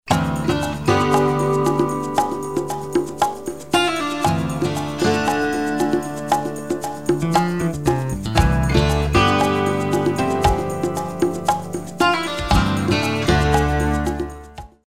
New Age Music Samples
New Age 07a